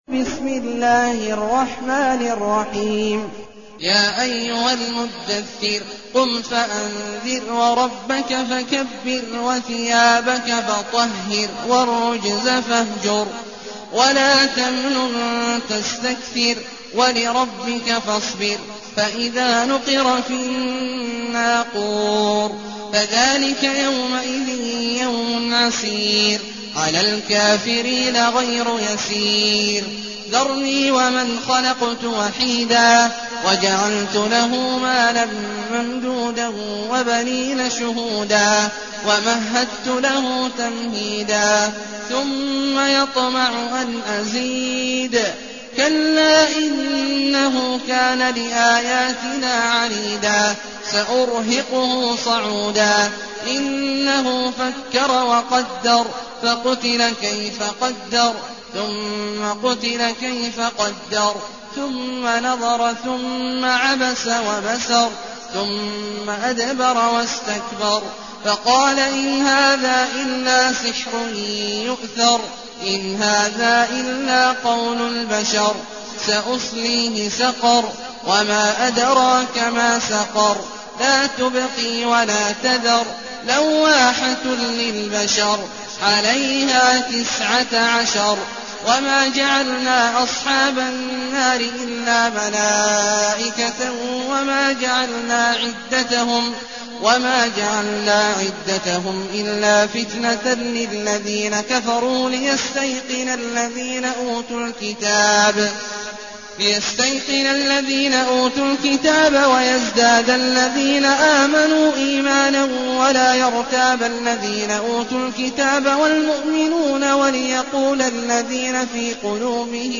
المكان: المسجد النبوي الشيخ: فضيلة الشيخ عبدالله الجهني فضيلة الشيخ عبدالله الجهني المدثر